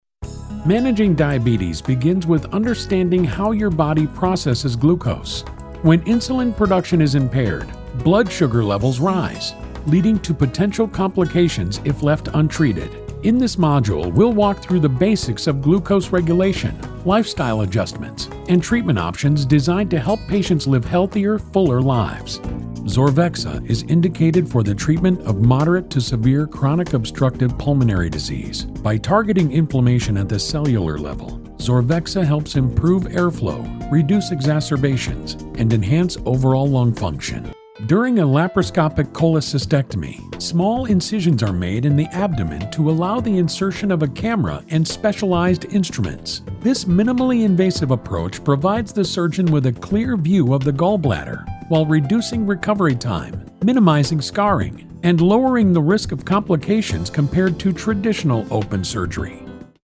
Inglés (Estados Unidos)
Narración médica
Micrófono Neumann TLM102